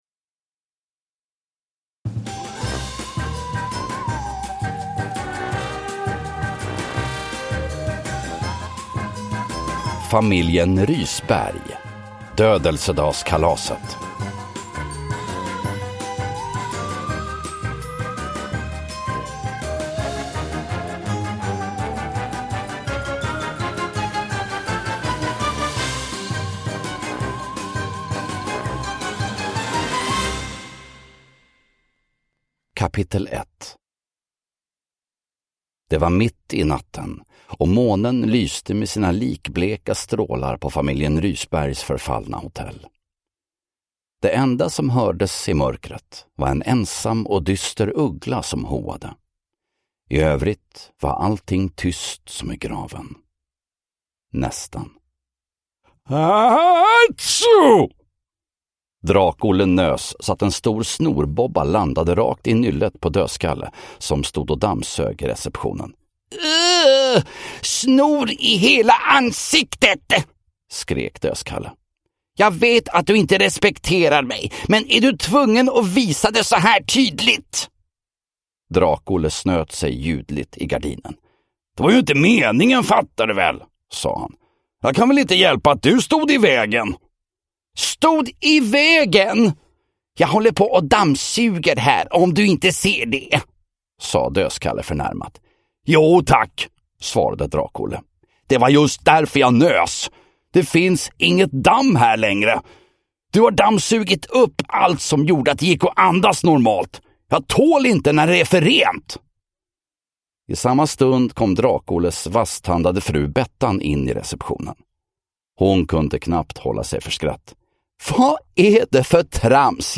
Dödelsedagskalaset – Ljudbok